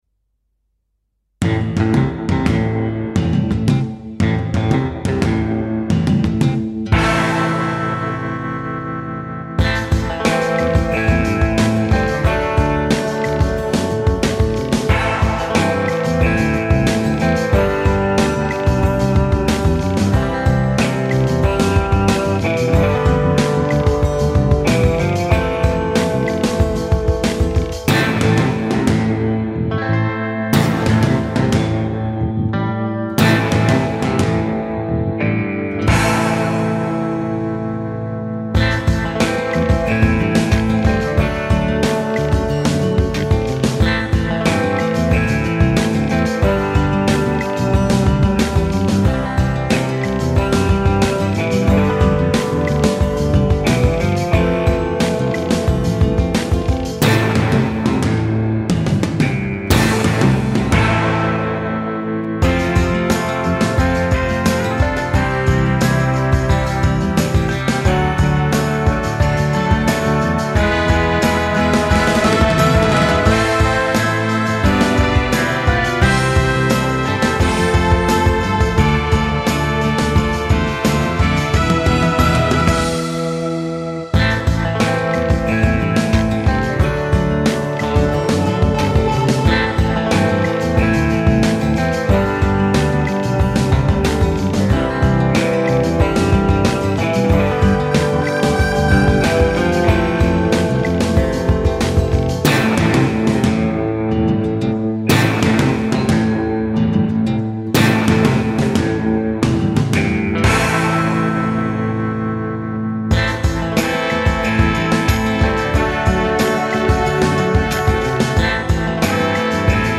suspense - western
guitare electrique - paysages